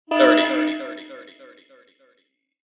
selfdestructthirty.ogg